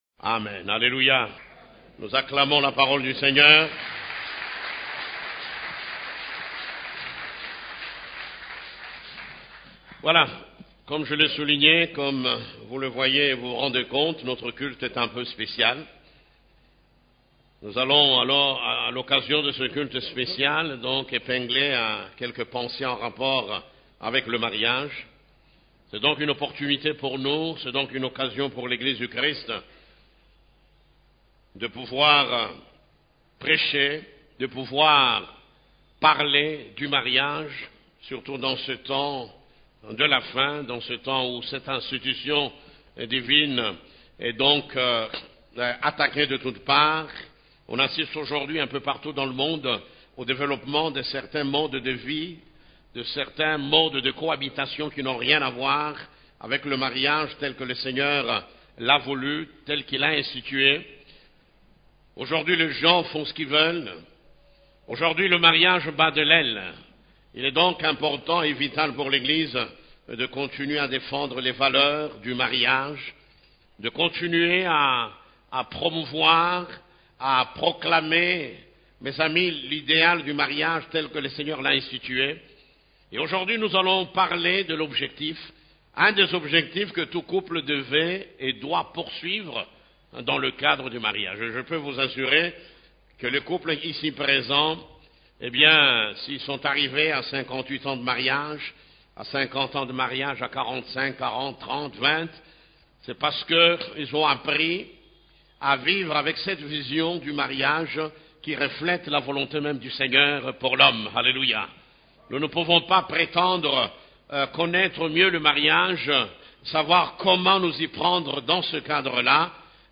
Culte du Dimanche, Visez l'or dans le mariage